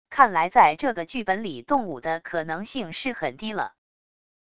看来在这个剧本里动武的可能性是很低了wav音频生成系统WAV Audio Player